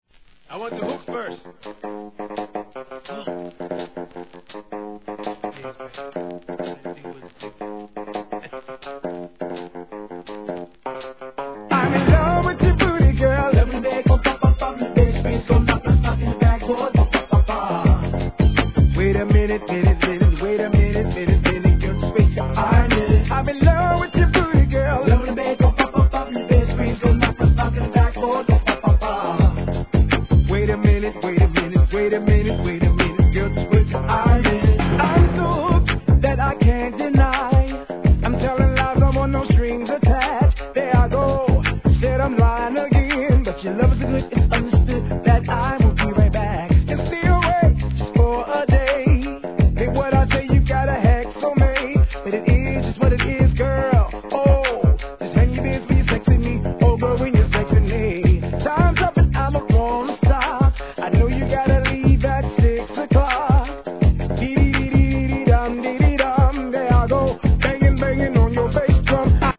HIP HOP/R&B
頭から離れないギター・リフとシンセの上音で歌い上げるインディーR&B。